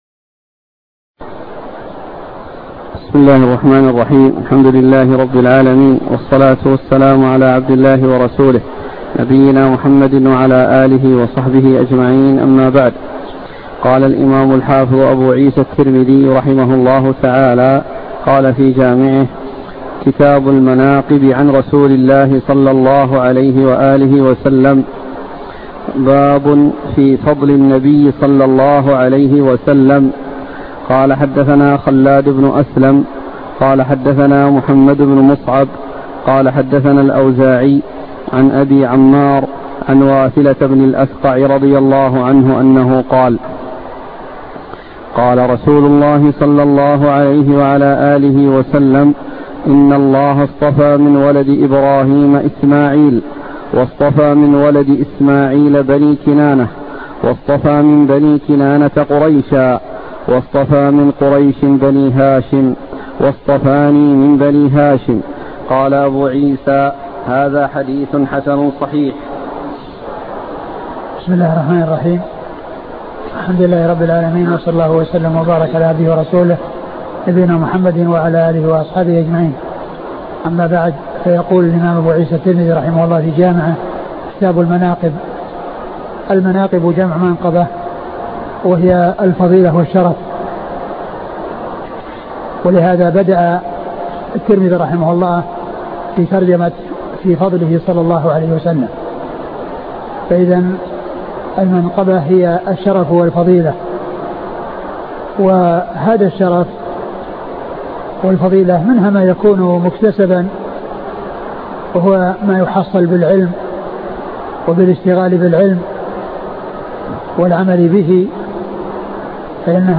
سنن الترمذي شرح الشيخ عبد المحسن بن حمد العباد الدرس 395